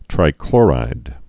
(trī-klôrīd) also tri·chlo·rid (-klôrĭd)